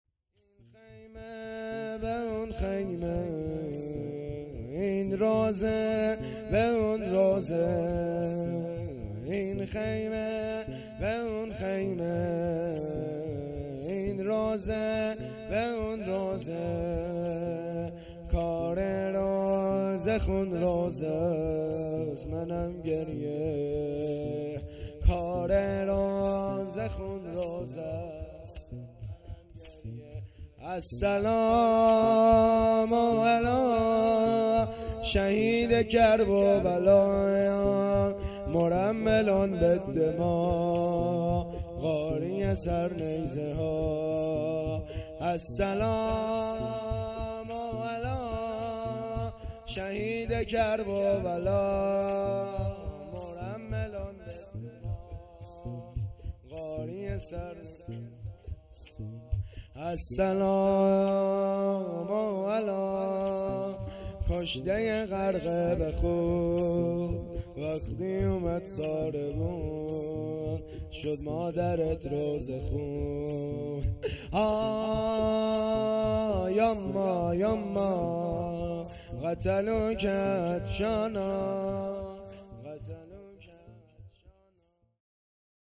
شام شهادت امام حسن مجتبی ع(۷صفر)- سال ۱۴۰۰